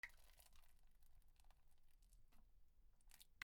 / J｜フォーリー(布ずれ・動作) / J-25 ｜おなら・大便